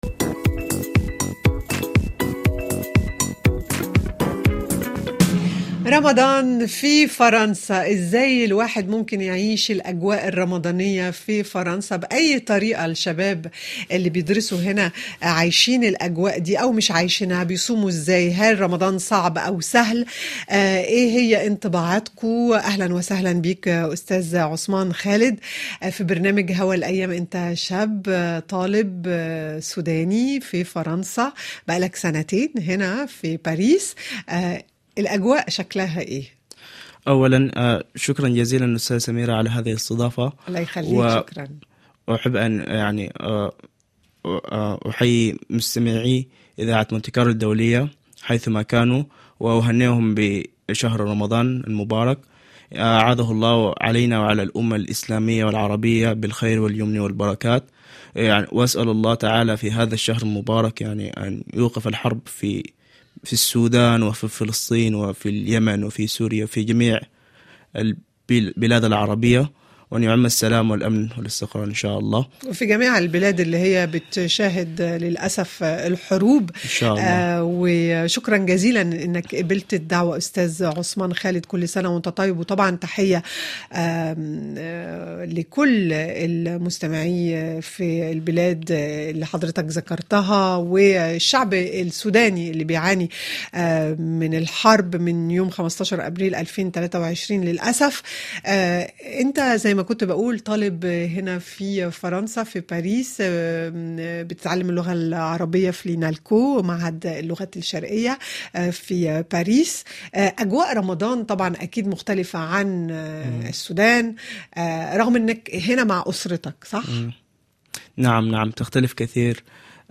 طالبان مغتربان يعيشان تجربة رمضان في فرنسا